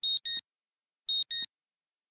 Alarm_Beep_01.ogg